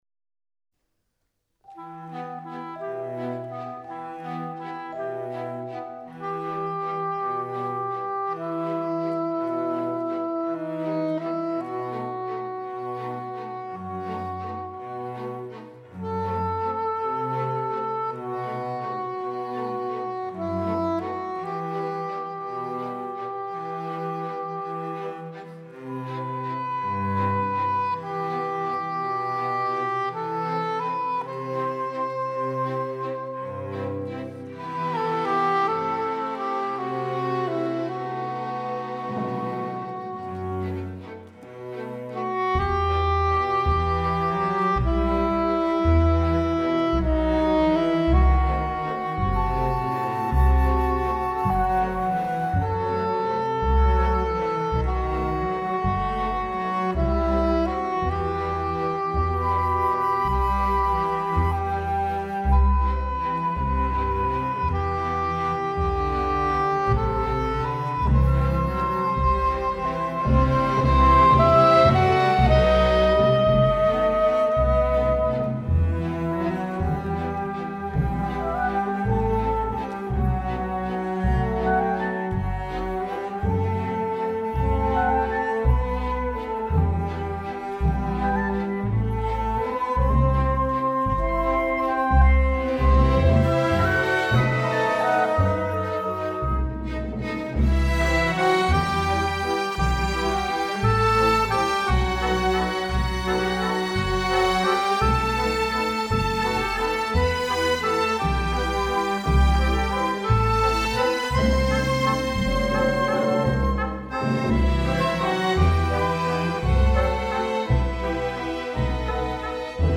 Επτά μουσικά θέματα ενορχηστρωμένα από τον συνθέτη
όπως και ελεύθερα  μουσικά θέματα κινηματογραφικής χροιάς.